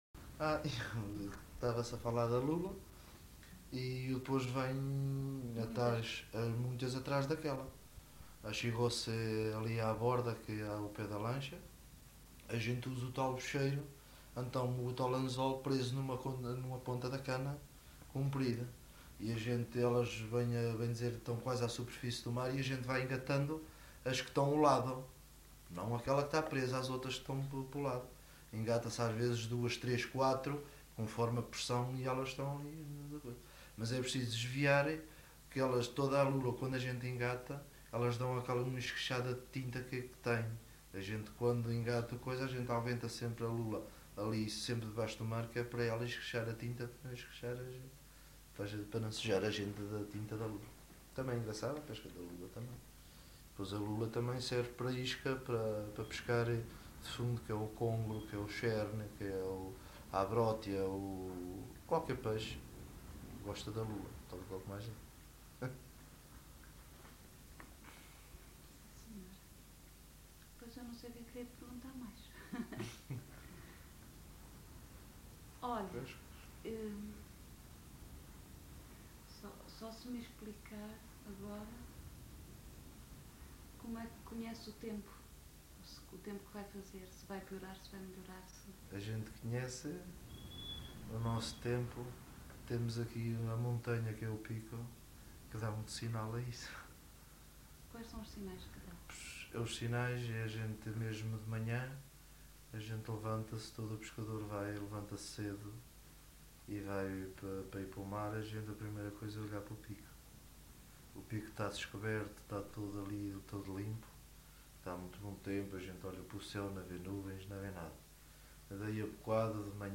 LocalidadeMadalena (Madalena, Horta)